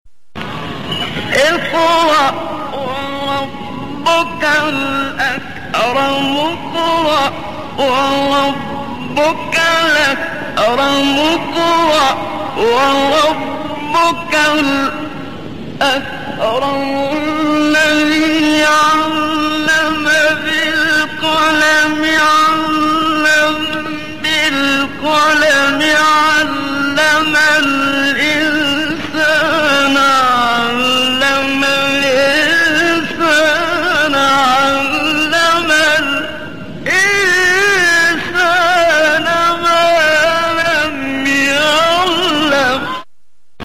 گروه فعالیت‌های قرآنی: فرازهای صوتی از قراء برجسته جهان اسلام را می‌شنوید.
فرازی از شیخ محمد رشاد الشریف مرتل مسجد الاقصی